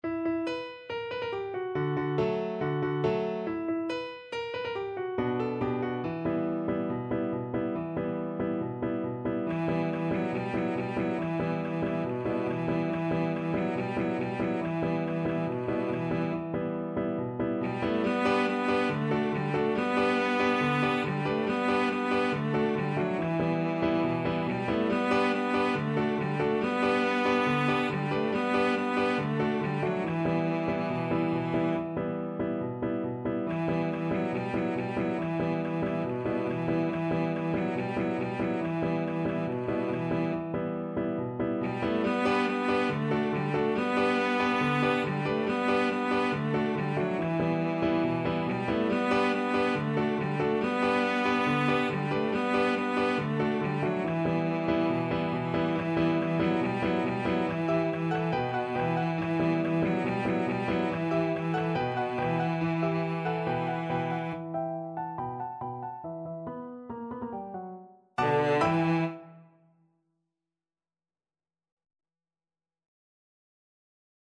Cello
E minor (Sounding Pitch) (View more E minor Music for Cello )
Very Fast =c.140
4/4 (View more 4/4 Music)
B3-B4
Israeli